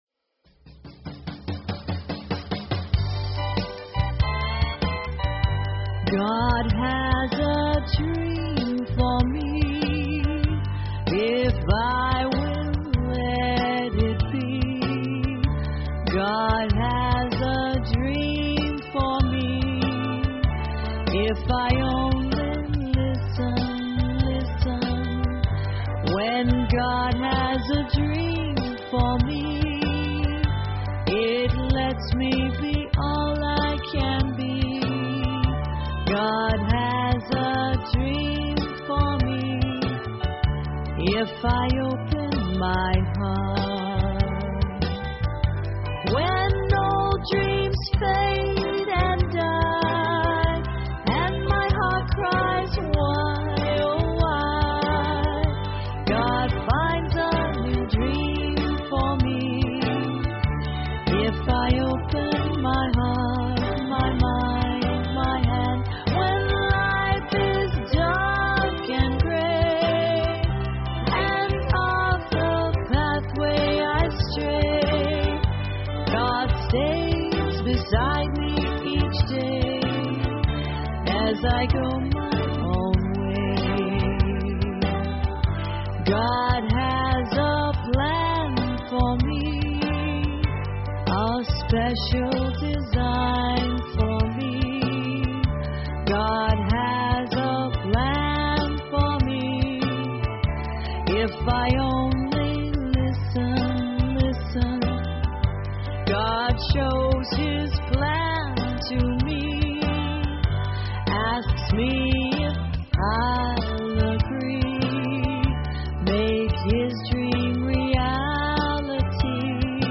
Talk Show Episode, Audio Podcast, Inner_Garden_Online_Chapel and Courtesy of BBS Radio on , show guests , about , categorized as